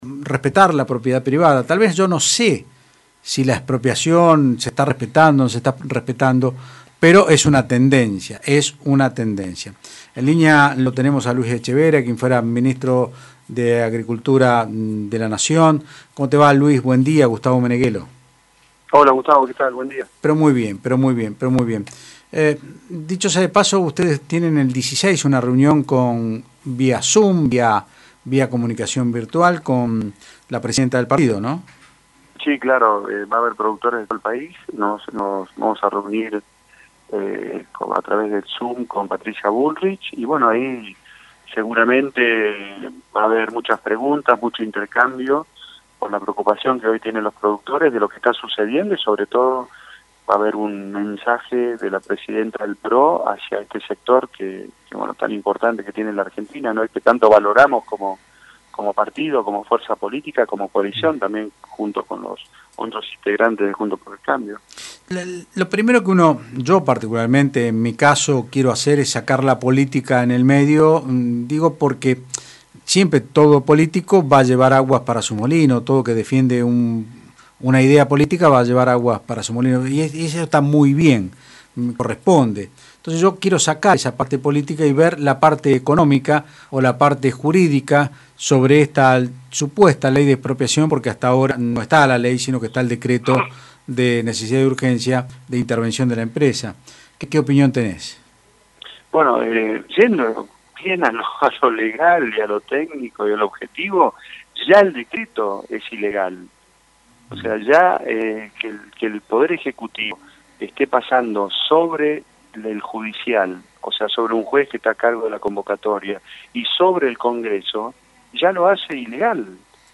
El Expresidente de la Sociedad Rural y exministro de Agroindustria Luis Etchevehere consultado en Otros Ámbitos (Del Plata Rosario 93.5) sobre el proyecto de expropiación e intervención de Vicentin dijo que es “una muy mala señal” y comparó la situación con Venezuela.